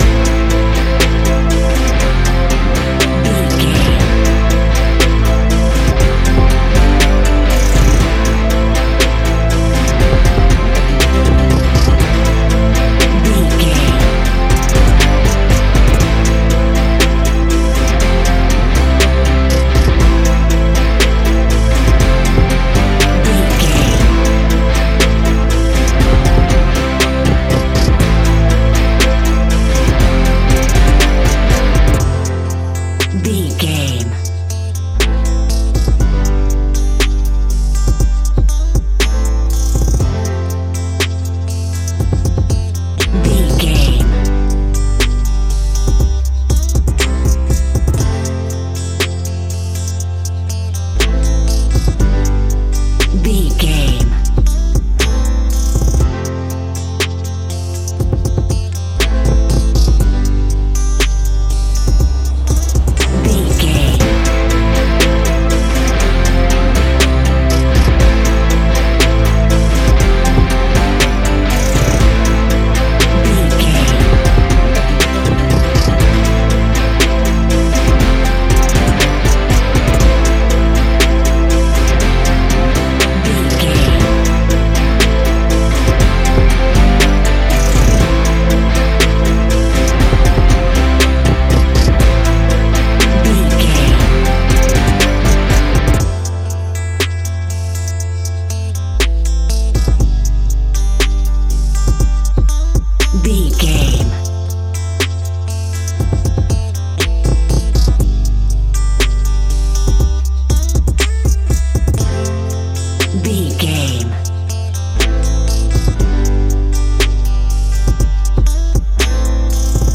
Ionian/Major
A♭
ambient
electronic
chill out
downtempo
synth
pads
instrumentals